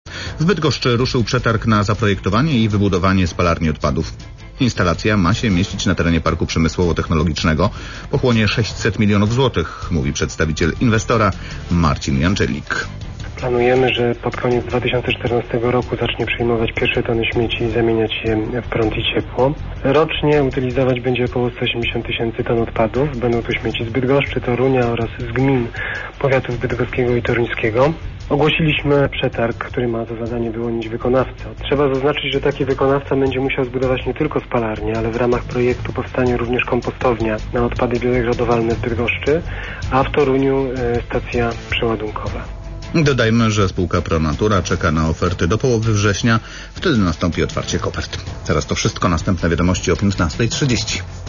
wiadomosci-pr-pik.wav